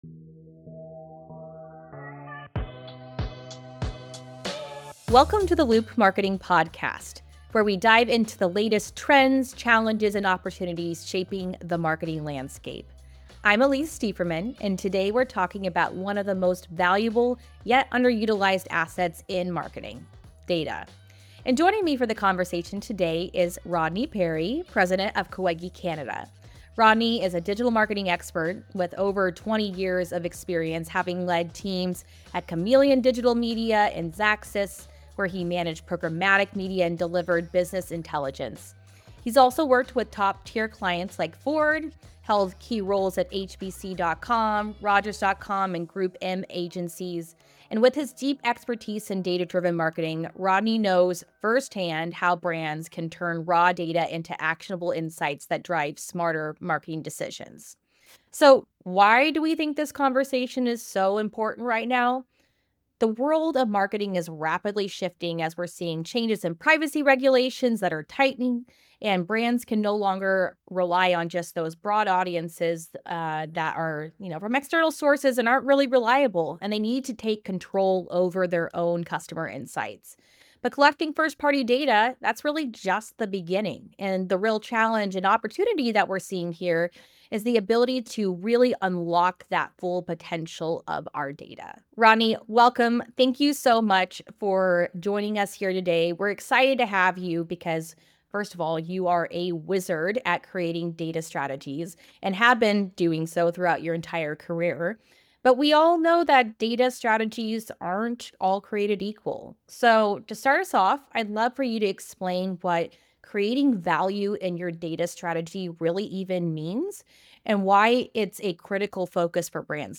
The conversation delves into creating value from data, integrating insights across teams, and building comprehensive data strategies that enhance business intelligence.